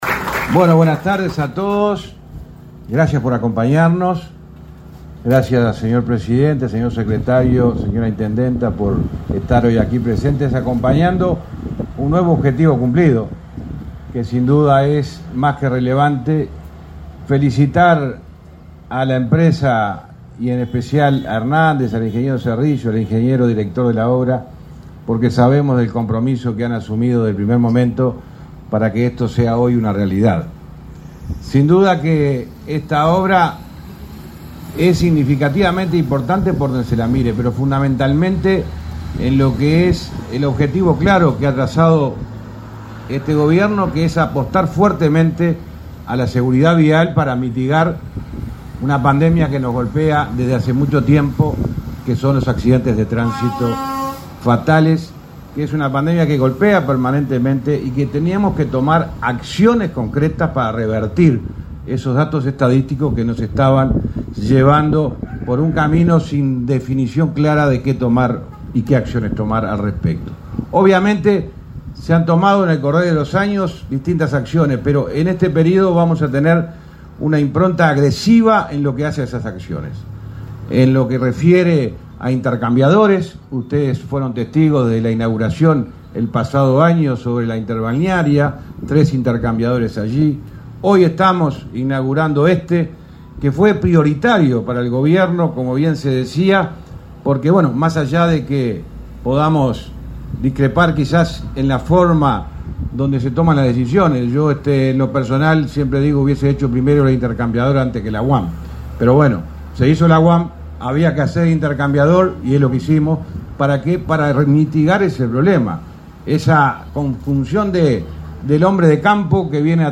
Palabras del ministro de Transporte y Obras Públicas, José Luis Falero
Palabras del ministro de Transporte y Obras Públicas, José Luis Falero 01/12/2022 Compartir Facebook X Copiar enlace WhatsApp LinkedIn Con la presencia del presidente de la República, Luis Lacalle Pou, y el secretario de la Presidencia, Álvaro Delgado, se inauguró, este 1.º de diciembre, un intercambiador en la ruta 5 y el camino Luis Eduardo Pérez. En el evento se expresó el ministro de Transporte y Obras Públicas, José Luis Falero.